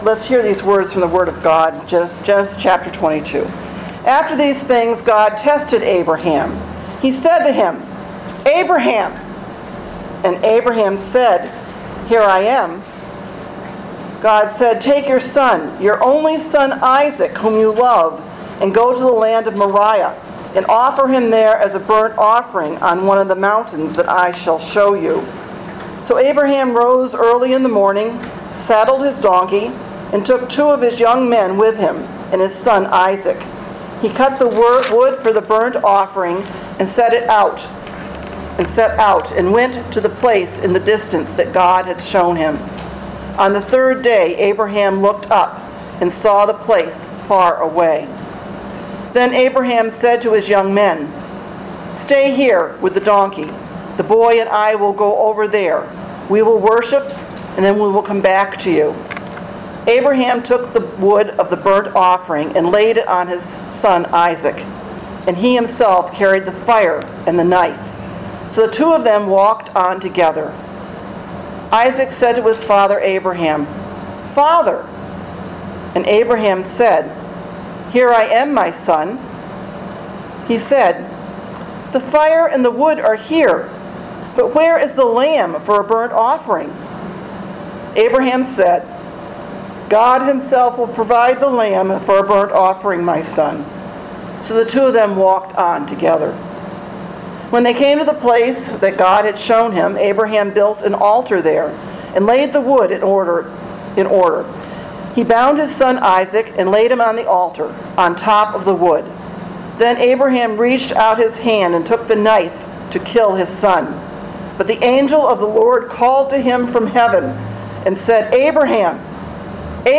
Sermon: Things We’d Like To Forget?